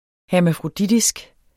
Udtale [ hæɐ̯mafʁoˈdidisg ]